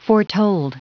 Prononciation du mot foretold en anglais (fichier audio)
Prononciation du mot : foretold